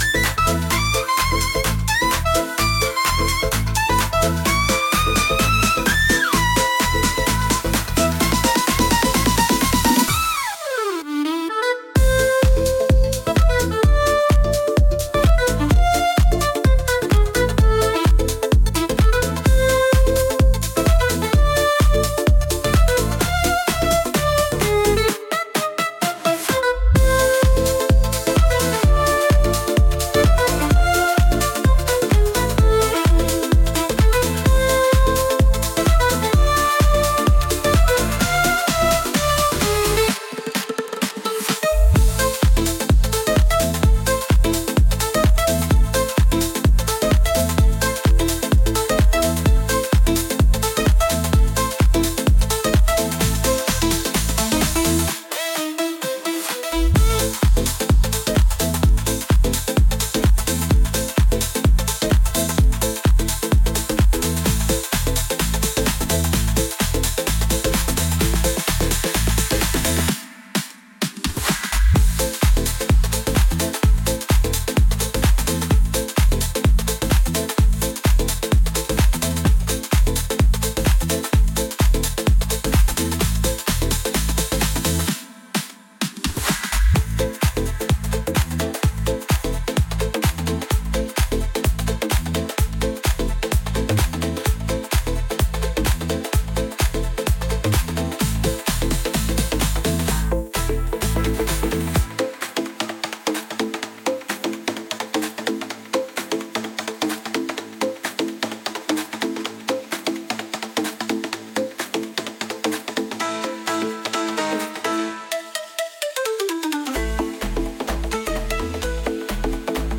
タイトルが全てを物語る、「そのまんま」の直球EDM！
余計な装飾を削ぎ落とした、ビートとシンセサイザーの響きが、フロアを瞬時にクラブハウスへと変貌させます。